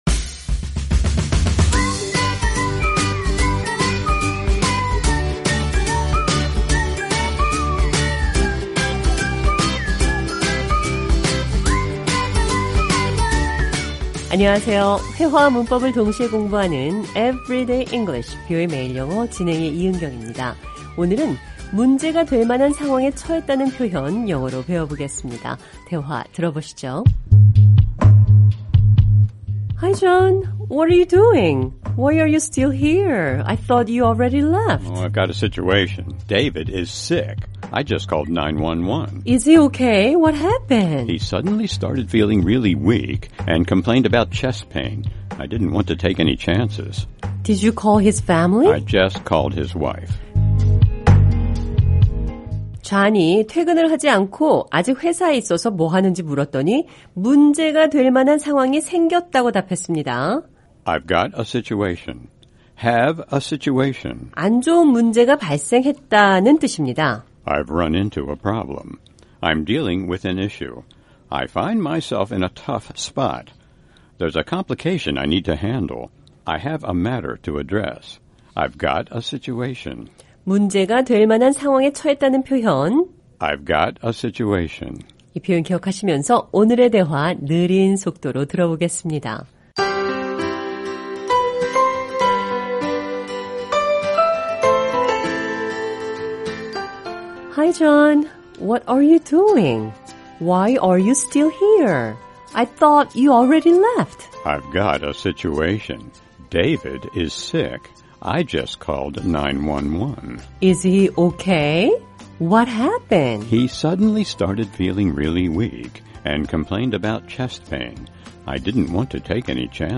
오늘은 문제가 될 만한 상황에 부닥쳤다는 표현 영어로 배워보겠습니다. 대화 들어보시죠.